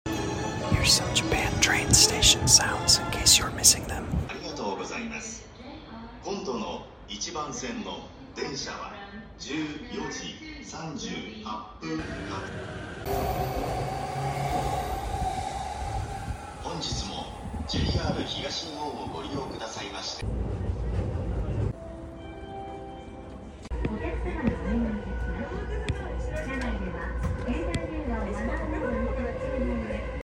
The sounds of Japan’s train sound effects free download
The sounds of Japan’s train stations are a symphony of everyday life. The rhythmic hum of arriving trains, the melodic jingles signaling departures, and the polite announcements create a uniquely Japanese atmosphere.